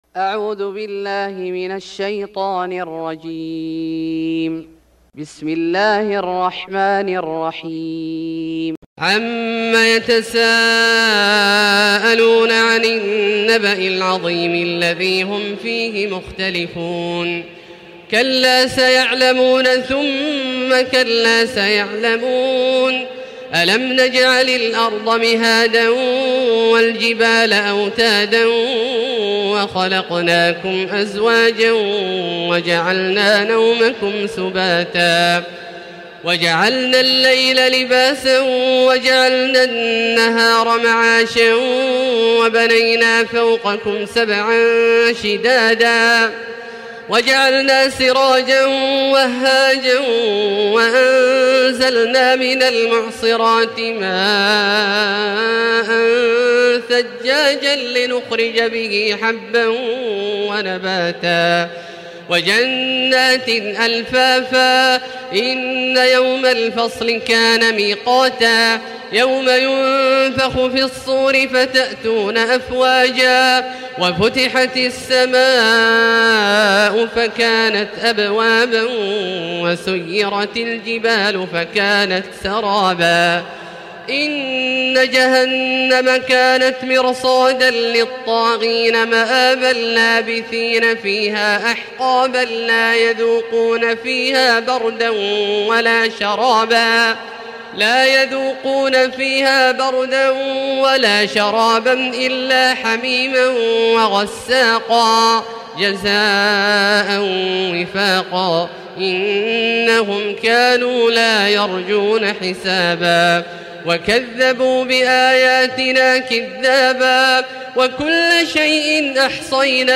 سورة النبأ Surat An-Naba > مصحف الشيخ عبدالله الجهني من الحرم المكي > المصحف - تلاوات الحرمين